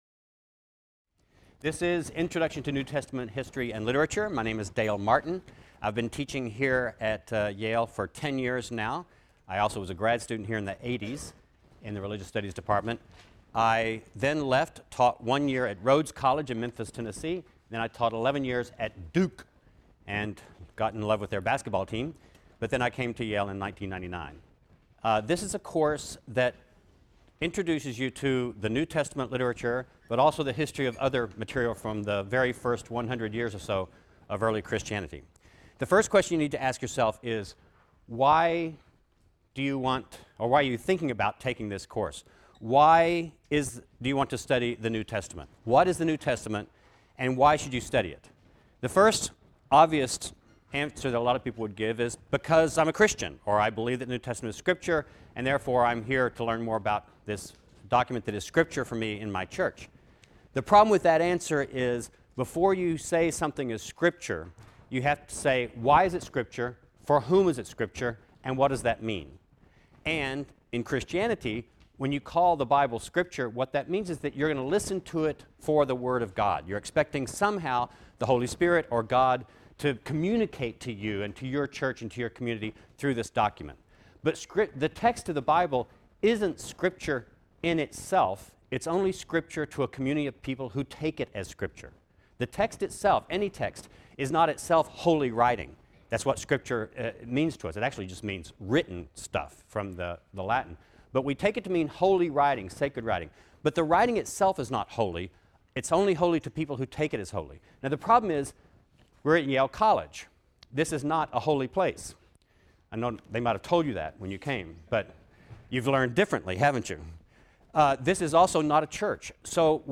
RLST 152 - Lecture 1 - Introduction: Why Study the New Testament?